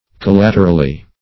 Collaterally \Col*lat"er*al*ly\, adv.